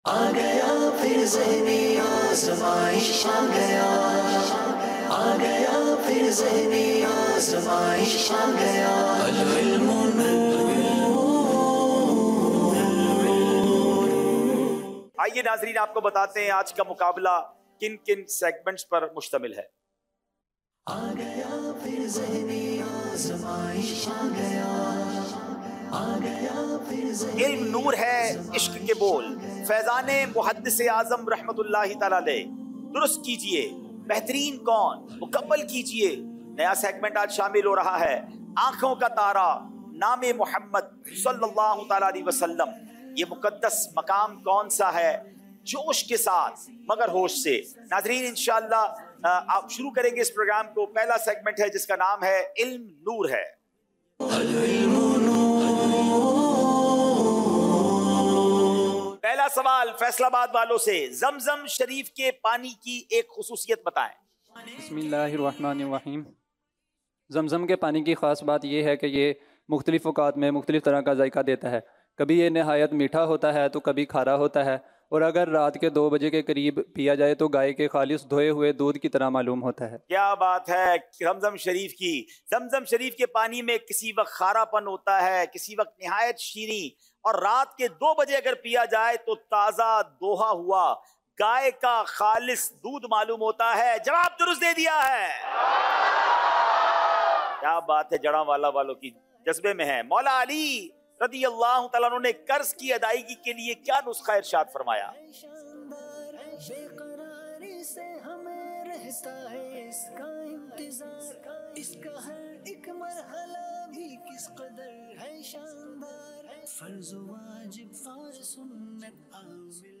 Highlights Complete Question & Answer Session
Jaranwala, Punjab | Zehni Azmaish Season 17